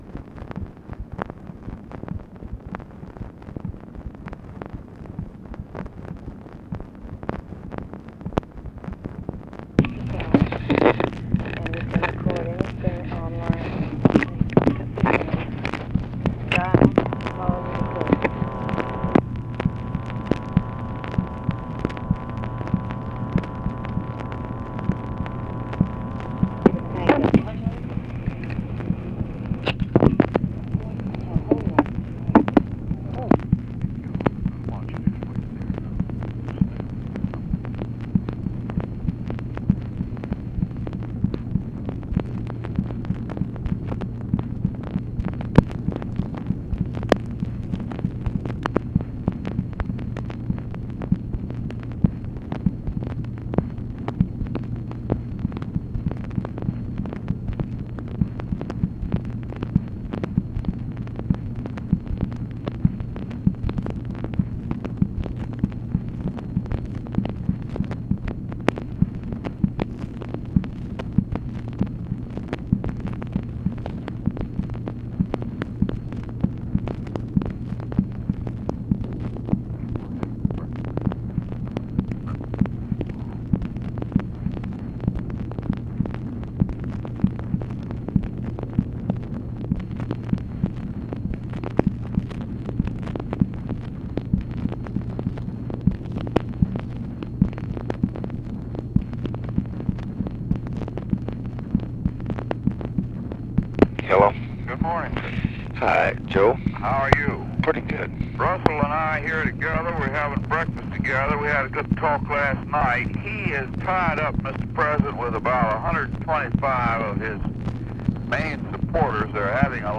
Conversation with HENRY FOWLER and RUSSELL LONG, November 29, 1965
Secret White House Tapes